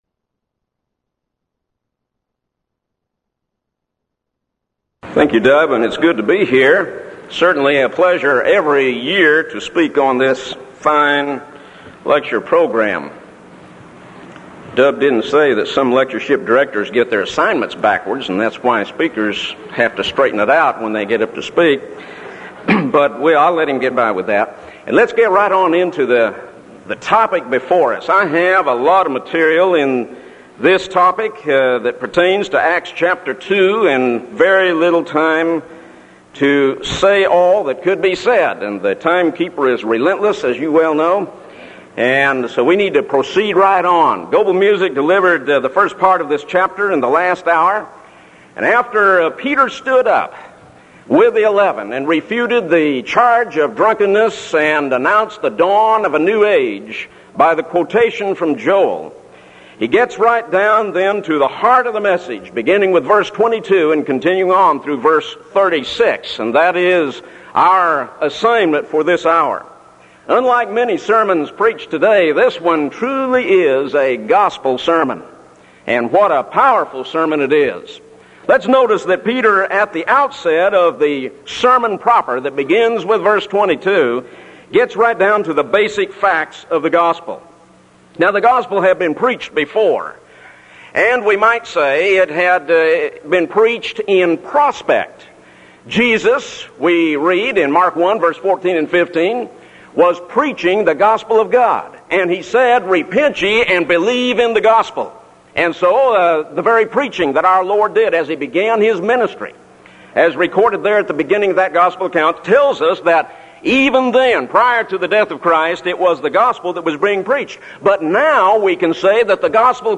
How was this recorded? Event: 1985 Denton Lectures Theme/Title: Studies in Acts